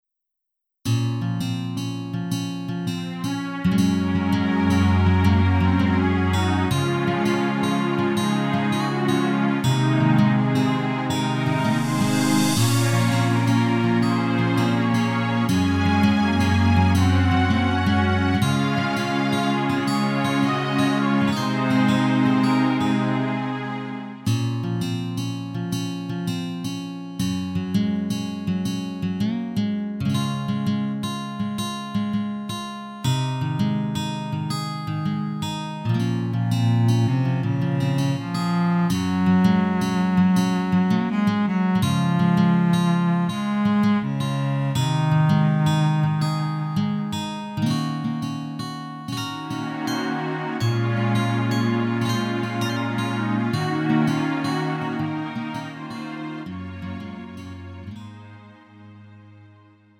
음정 원키 3:59
장르 가요 구분